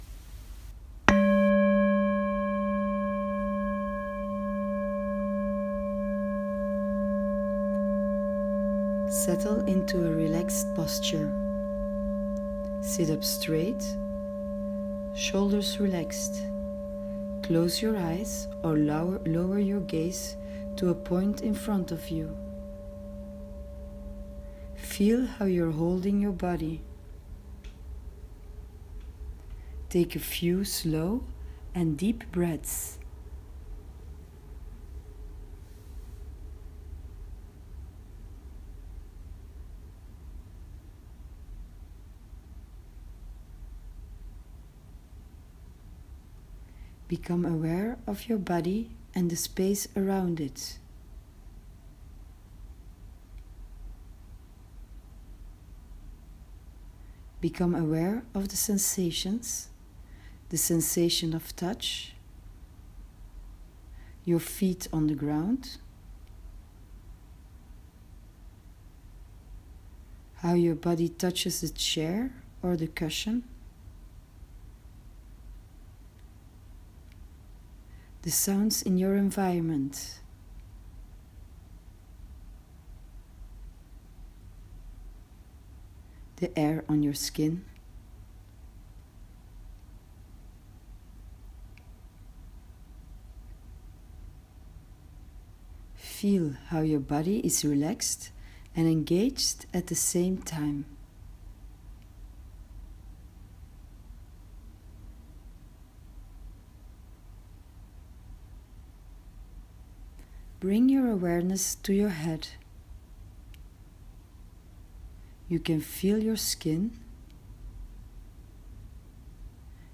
You’ll find the guided meditation as a voice recording under the screen with the introduction video.
meditation-wednesday-13-12.m4a